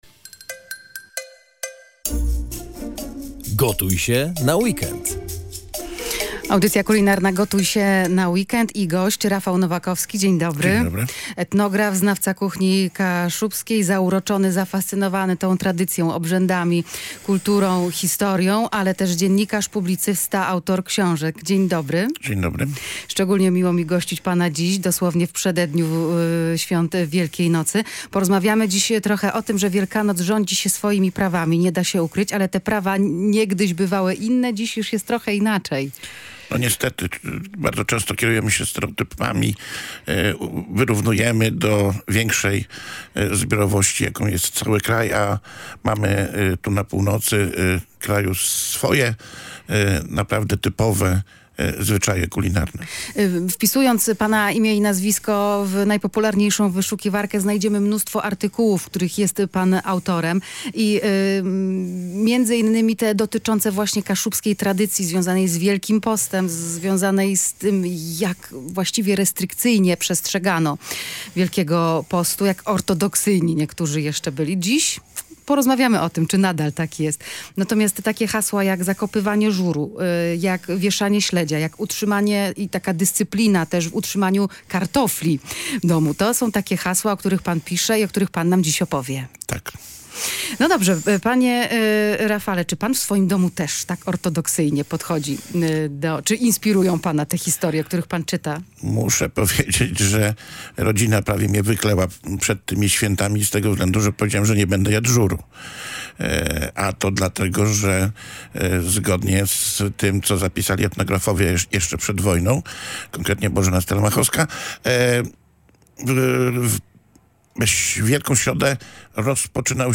Jak wyglądały w tradycji kaszubskiej? Wyjaśnia etnograf